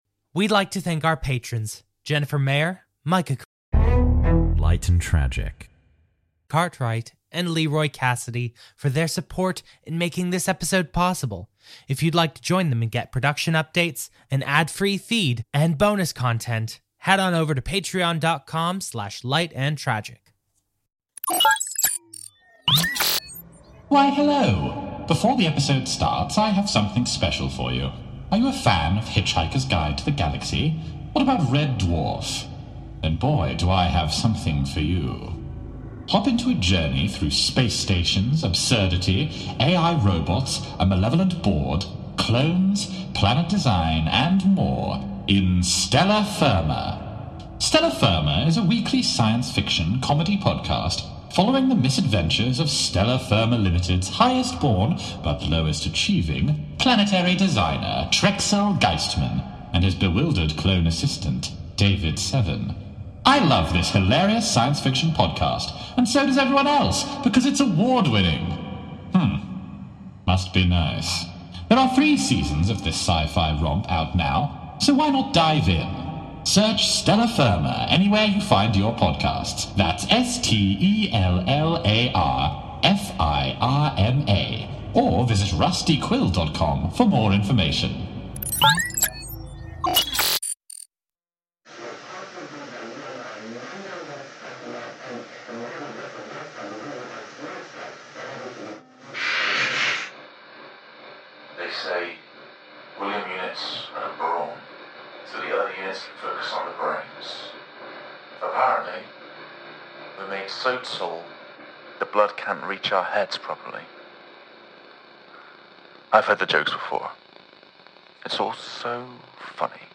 -Explosions